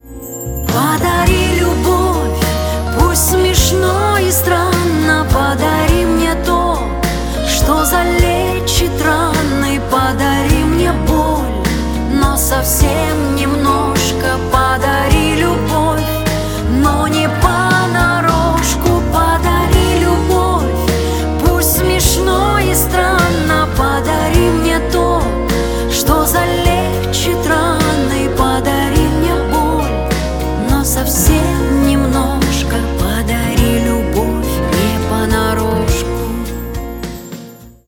Шансон
грустные